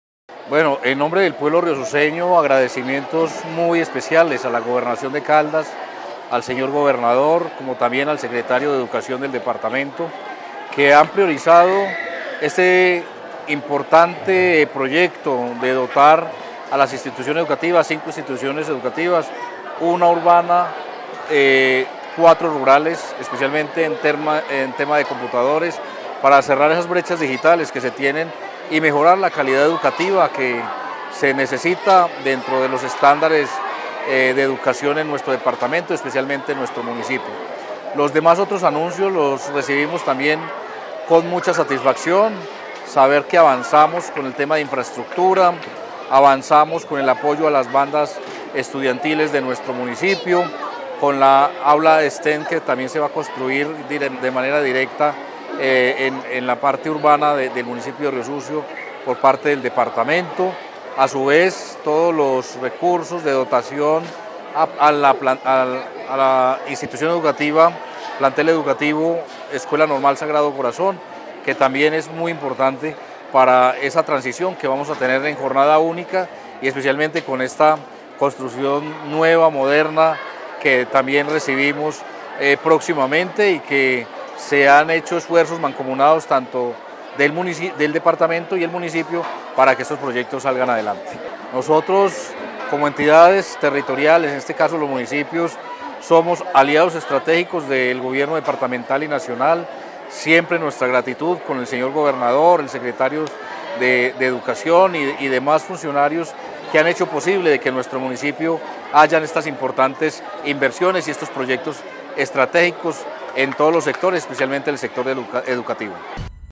Alcalde de Riosucio, Abel David Jaramillo.